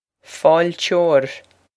fáilteoir foyle-chore
foyle-chore
This is an approximate phonetic pronunciation of the phrase.